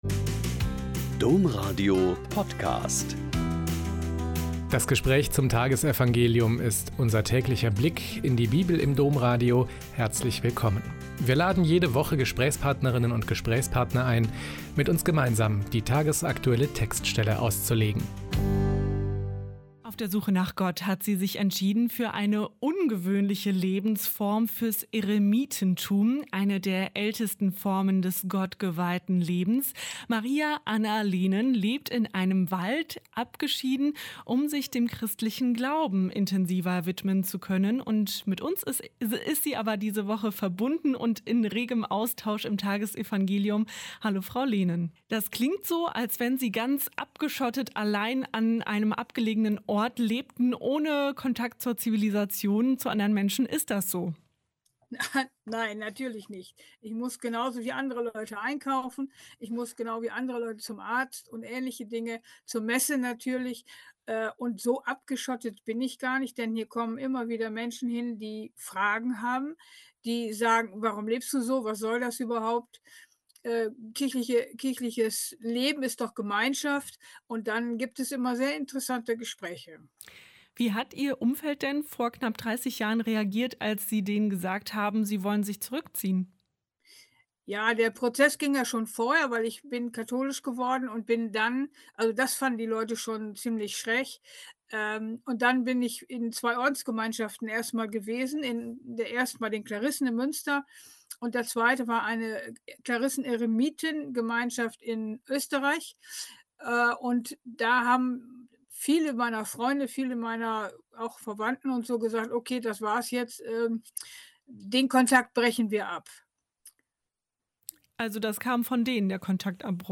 Mt 19,16-22 - Gespräch